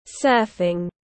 Môn lướt sóng tiếng anh gọi là surfing, phiên âm tiếng anh đọc là /ˈsɜː.fɪŋ/
Surfing /ˈsɜː.fɪŋ/